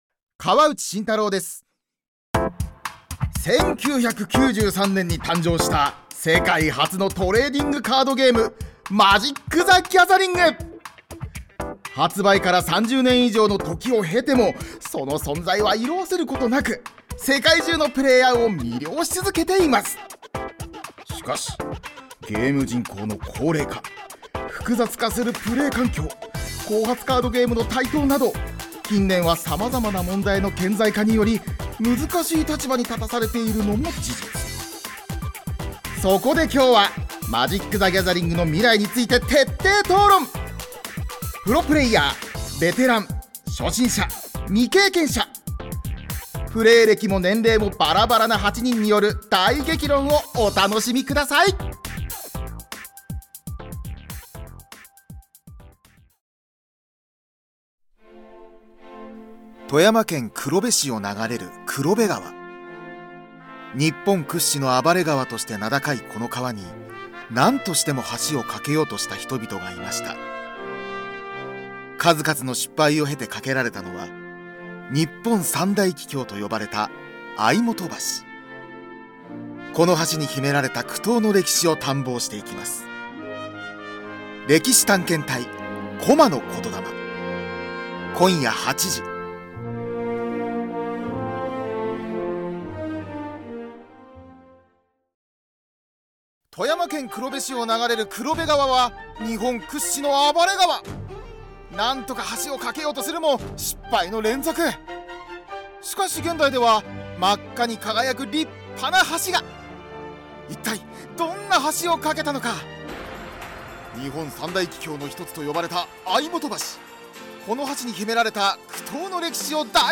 ナレーション